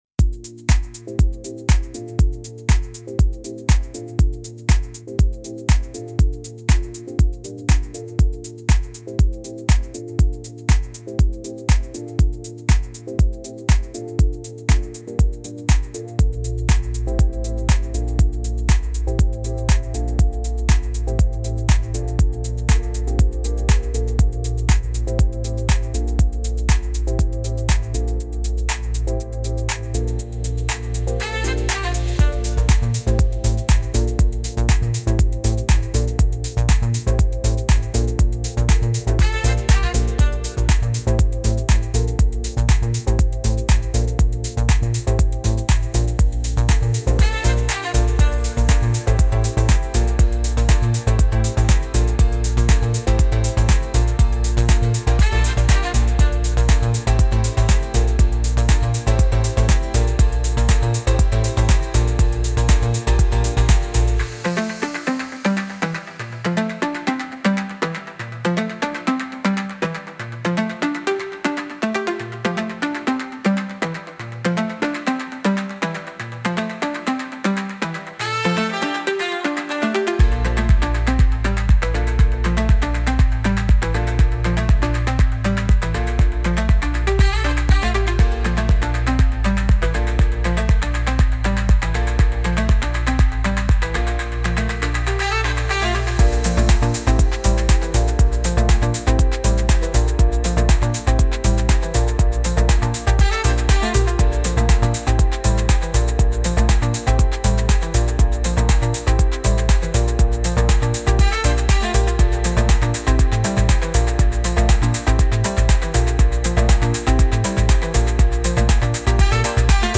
Instrumental - Real Liberty Media DOT xyz 4 min - Grimnir Radio